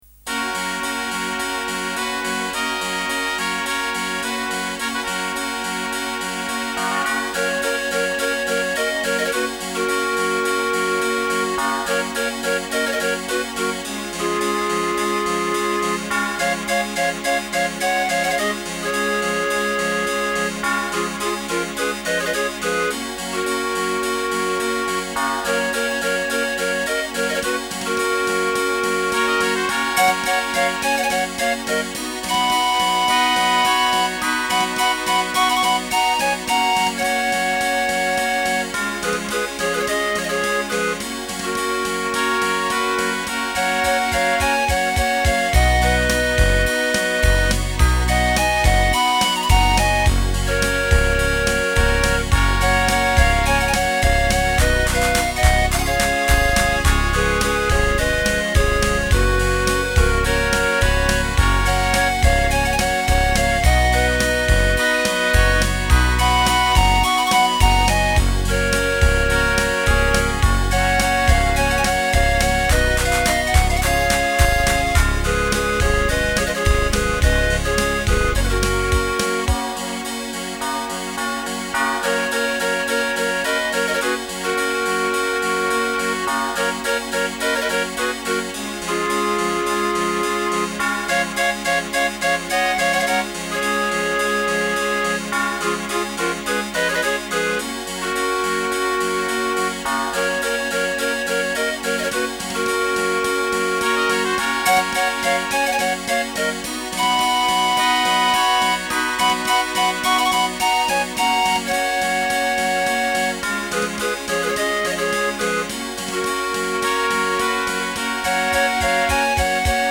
Melodierne fejler for saa vidt ikke noget, men de er helt sikkert ikke godt arrangeret.